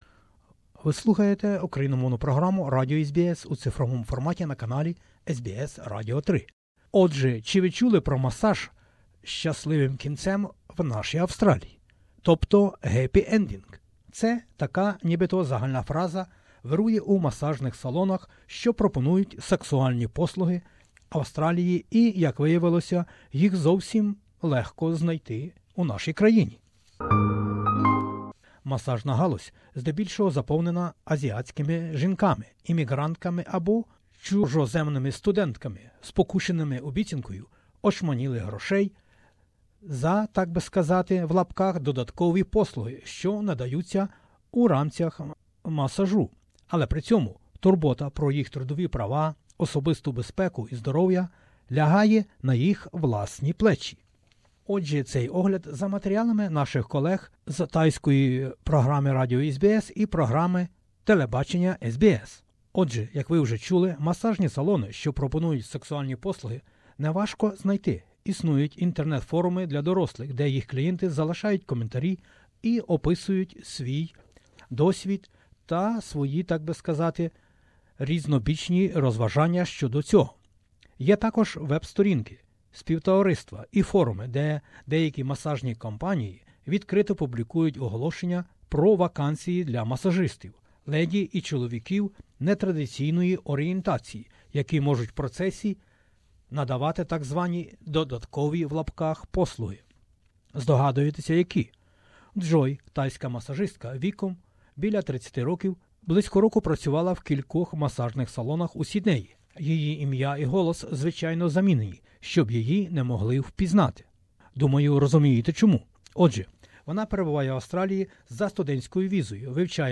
But they are often left to fend for themselves, in terms of their workplace rights, safety and health. ... has this report, compiled by SBS Radio's Thai language program and SBS Television's The Feed.